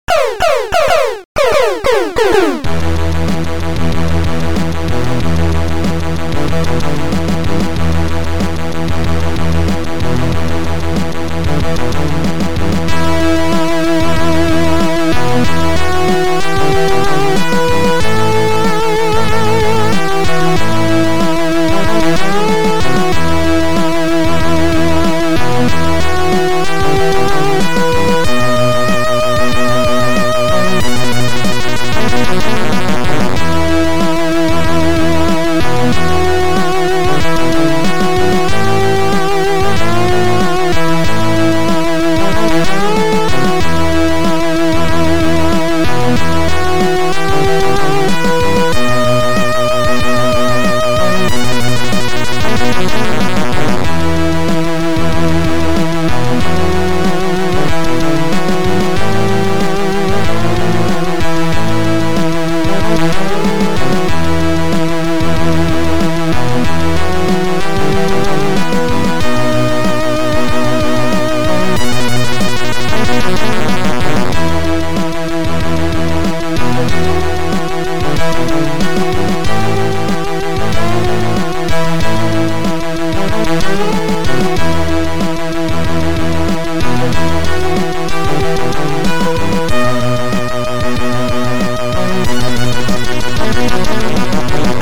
Synth
Future Composer Module